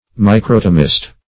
Microtomist \Mi*crot"o*mist\, n. One who is skilled in or practices microtomy.